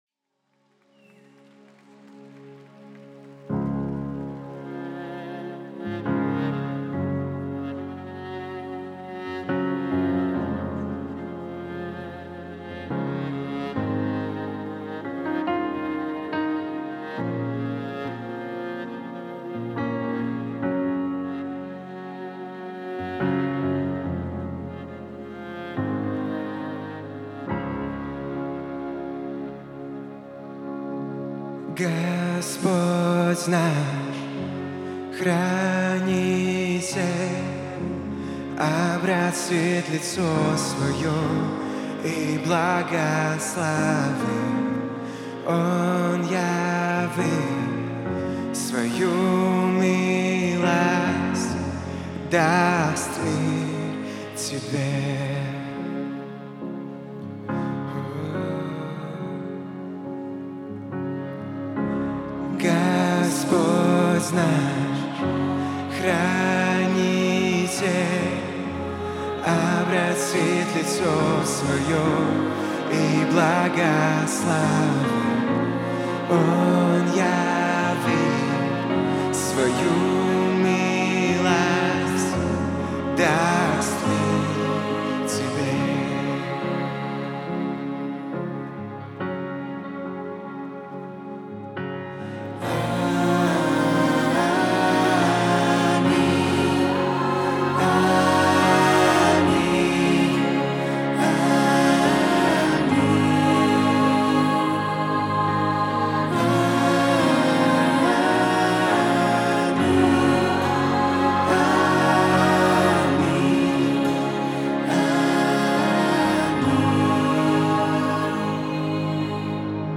152 просмотра 298 прослушиваний 14 скачиваний BPM: 70